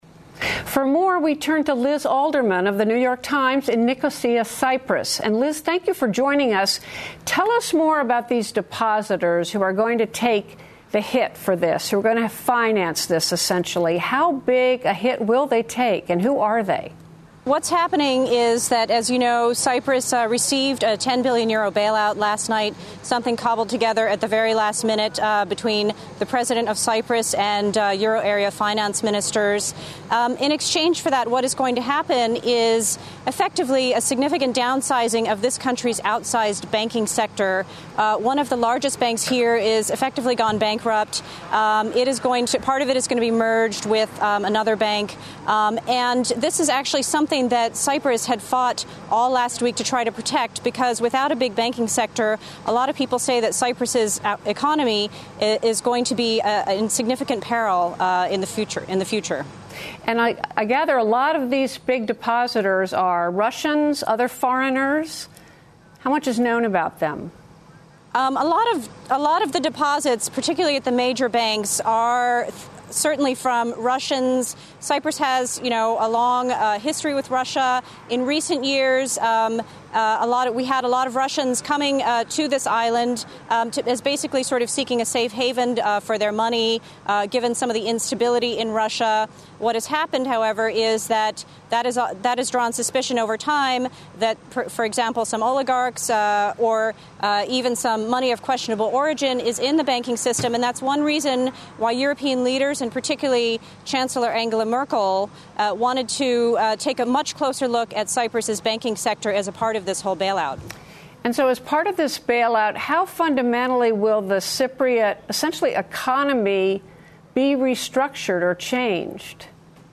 英语访谈节目:塞浦路斯银行危机引发对公正的质疑